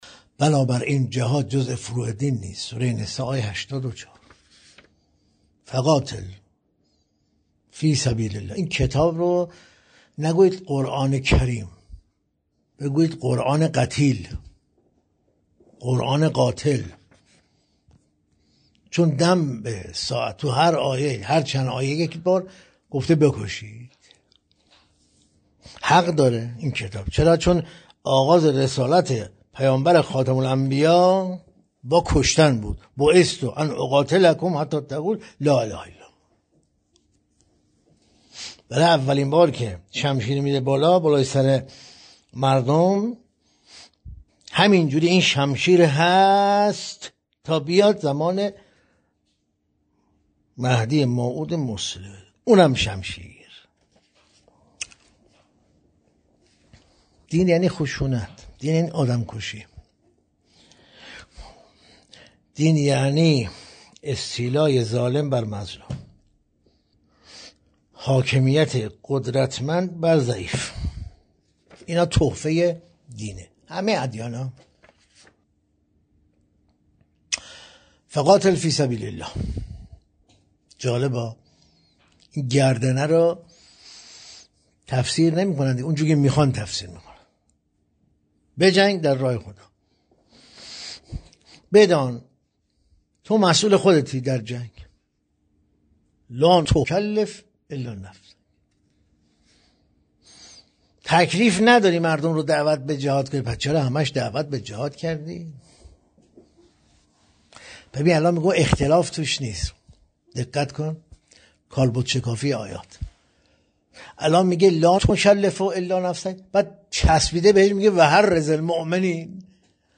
در این بخش، می‌توانید گزیده‌ای از تدریس‌های روزانه بروجردی، کاشف توحید بدون مرز، را مطالعه کرده و فایل صوتی آن را بشنوید.